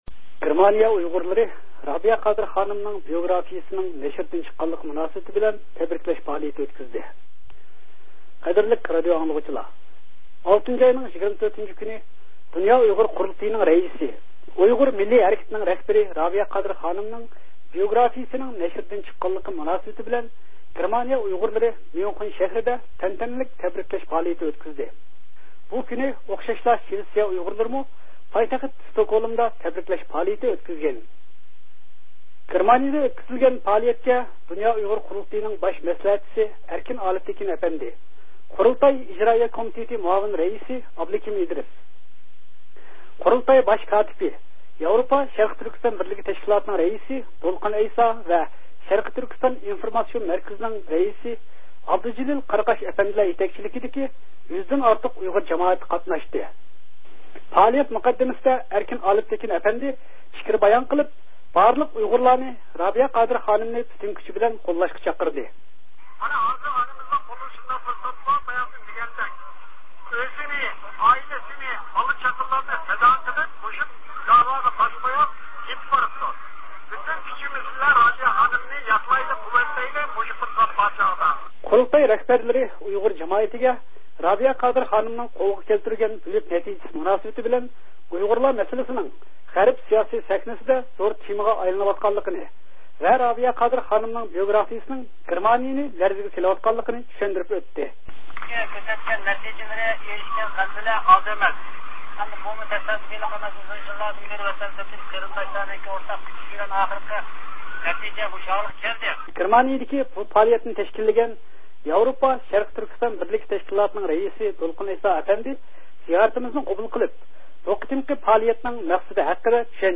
گېرمانىيىدىكى بۇ پائالىيەتنى تەشكىللىگەن ياۋروپا شەرقىي تۈركىستان بىرلىكى تەشكىلاتىنىڭ رەئىسى دولقۇن ئەيسا ئەپەندى زىيارىتىمىزنى قوبۇل قىلىپ، بۇ قېتىمقى پائالىيەتنىڭ مەقسىدى ھەققىدە چۈشەنچە بەردى ۋە د ئۇ ق نىڭ باش مەسلىھەتچىسى ئەركىن ئالىپتېكىن ئەپەندىنىڭ چاقىرىقى ھەققىدە توختىلىپ ئۆتتى.